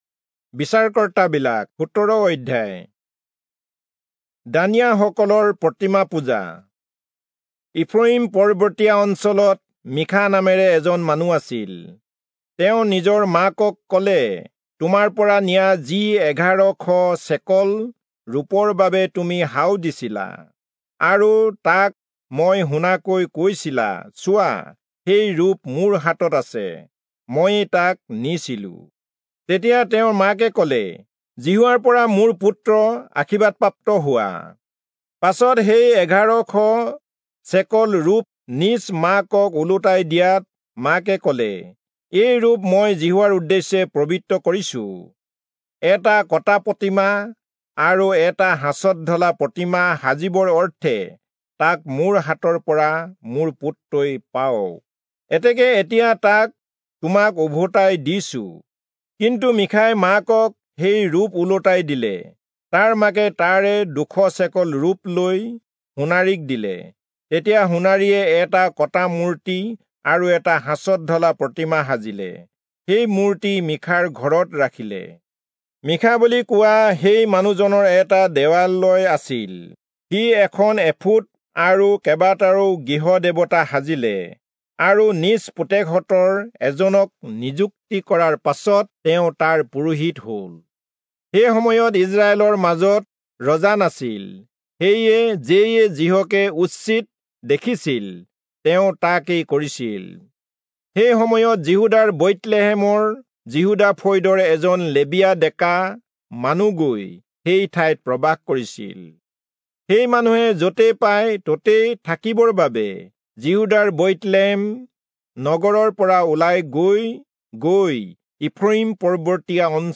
Assamese Audio Bible - Judges 4 in Gntwhrp bible version